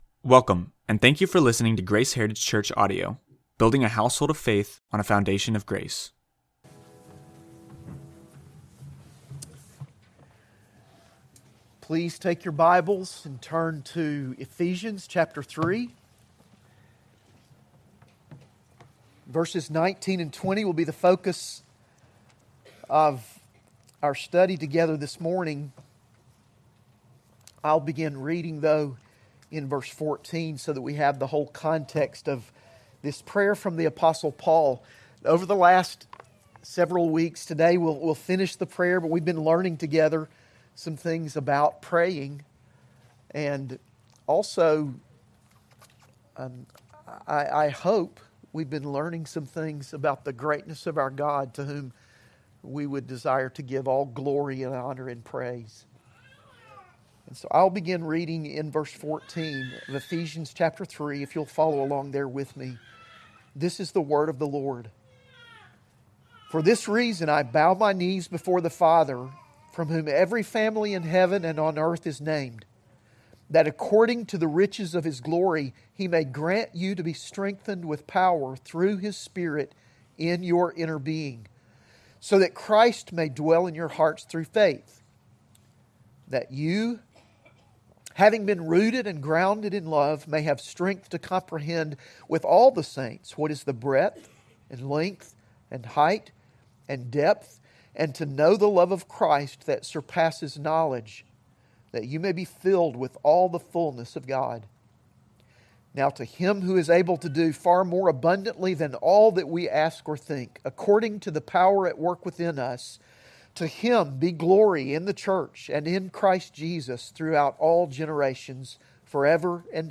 AM Worship Sermon